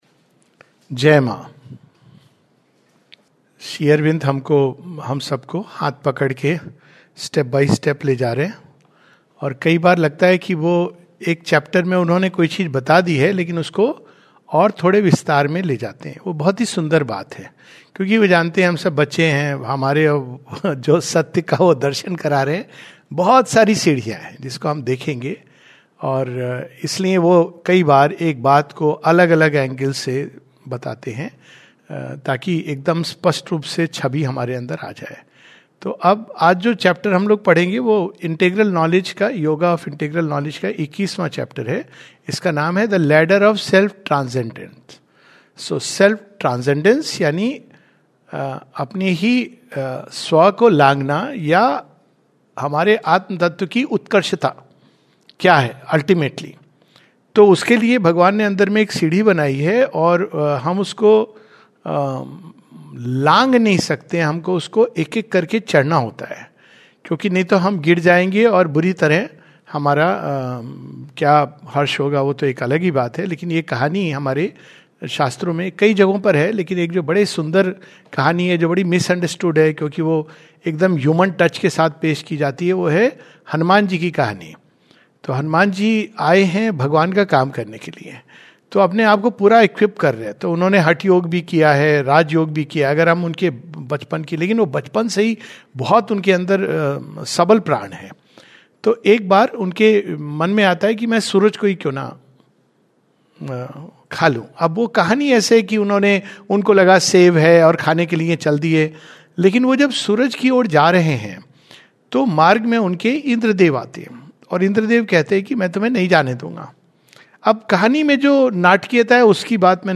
recorded on 14th April, 2026 in Pondicherry.